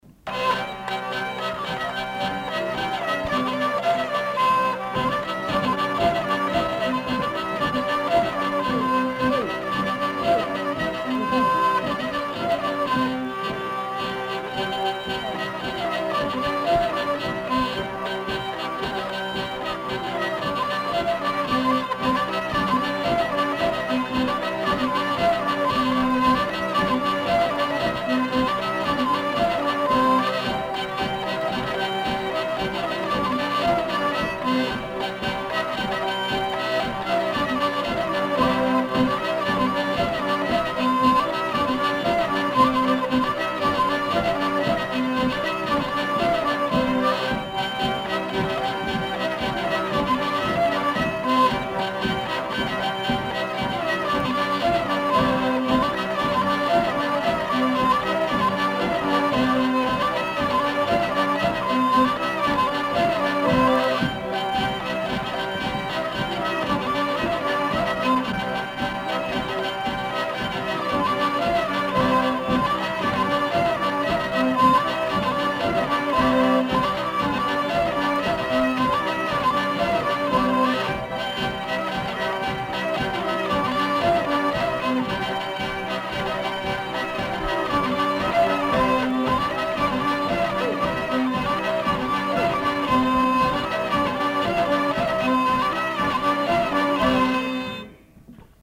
Aire culturelle : Savès
Genre : morceau instrumental
Instrument de musique : accordéon diatonique ; violon ; vielle à roue
Danse : rondeau